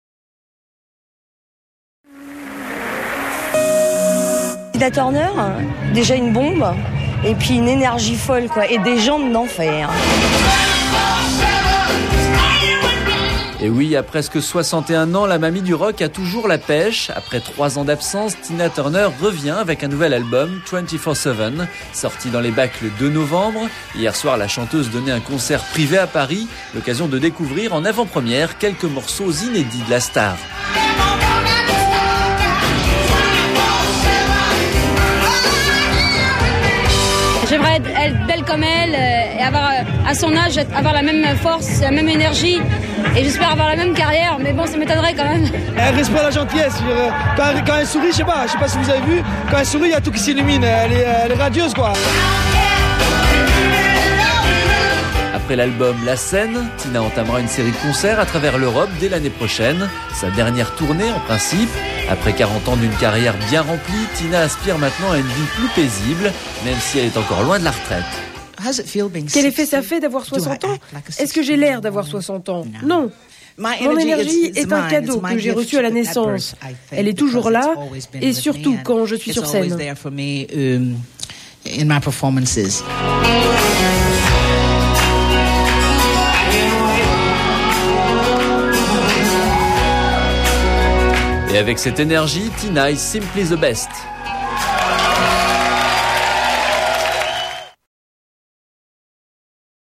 Report with song extracts (live) from Tina's new album "Twenty Four Seven" -